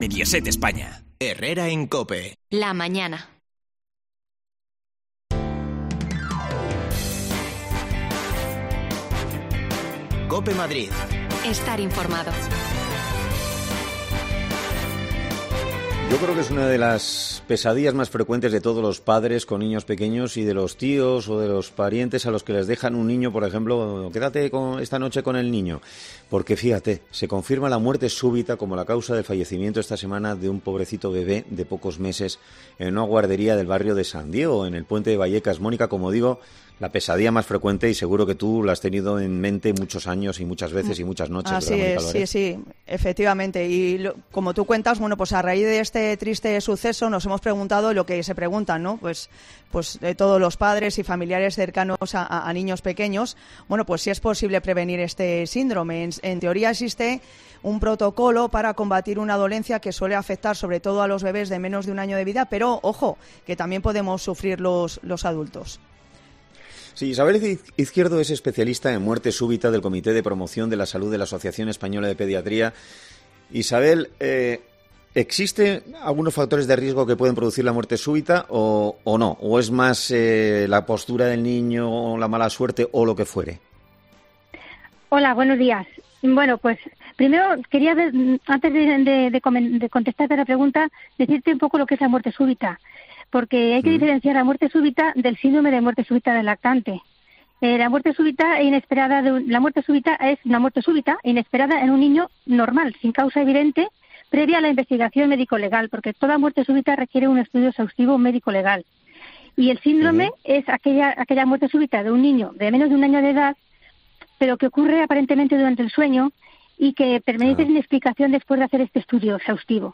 Herrera en COPE Madrid
Las desconexiones locales de Madrid son espacios de 10 minutos de duración que se emiten en COPE , de lunes a viernes.
Te contamos las últimas noticias de la Comunidad de Madrid con los mejores reportajes que más te interesan y las mejores entrevistas , siempre pensando en el ciudadano madrileño.